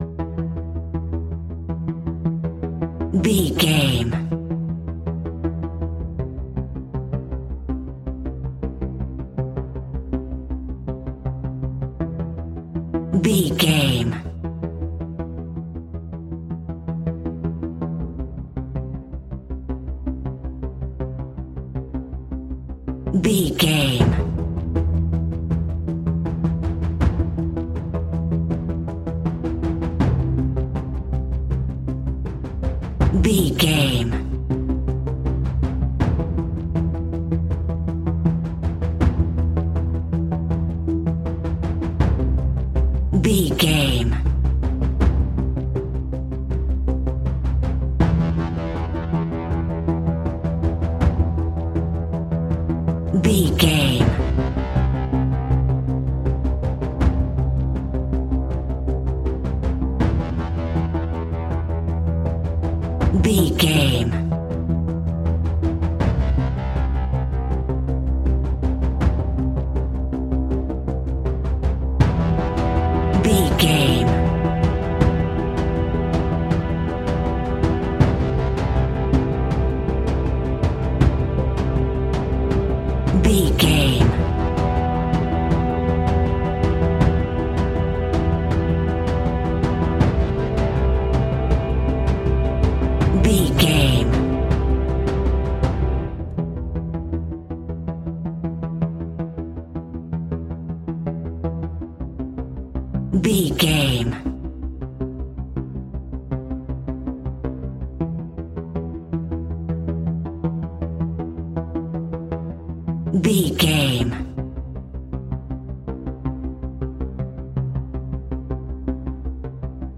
In-crescendo
Thriller
Aeolian/Minor
ominous
dark
haunting
eerie
synthesizer
percussion
instrumentals
horror music